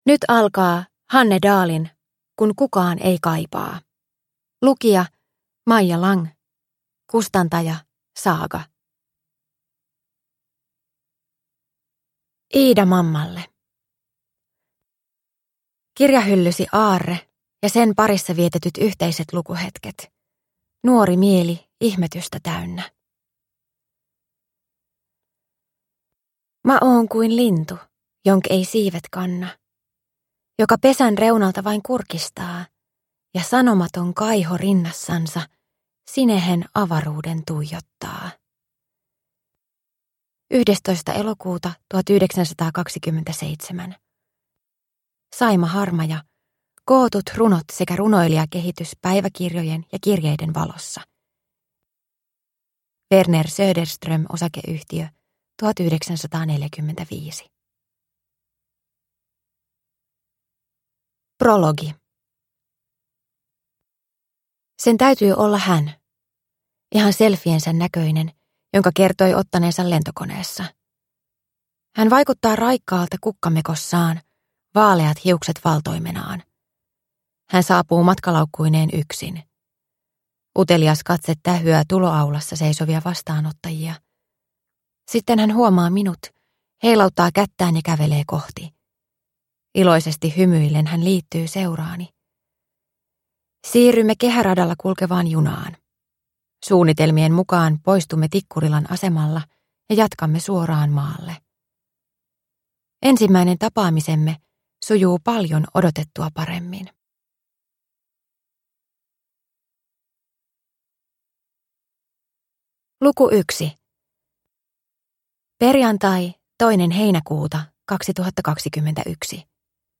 Kun kukaan ei kaipaa (ljudbok) av Hanne Dahl | Bokon